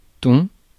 Ääntäminen
IPA: [tɔ̃]